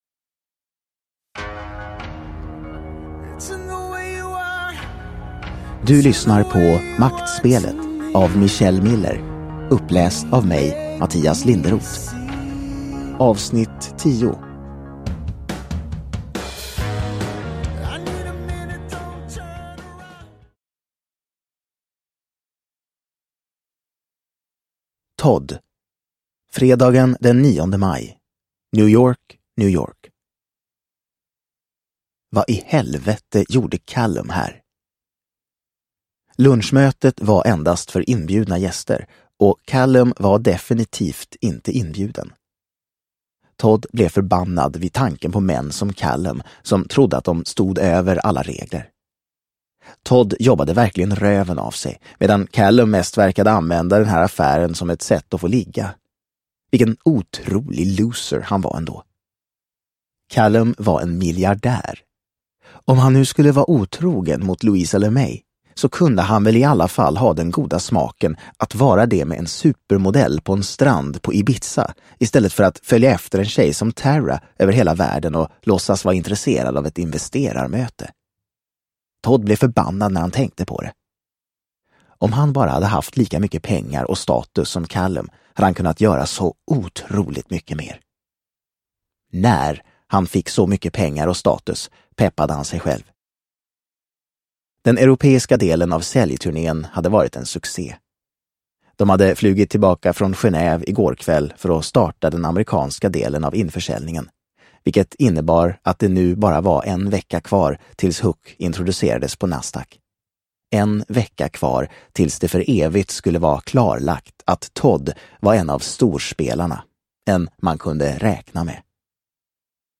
Maktspelet Del 10 – Ljudbok